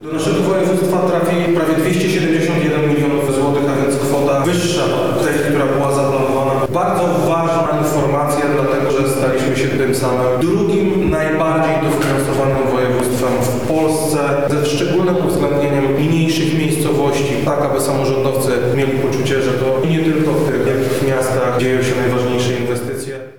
Krzysztof Komorski– mówi Krzysztof Komorski, Wojewoda Lubelski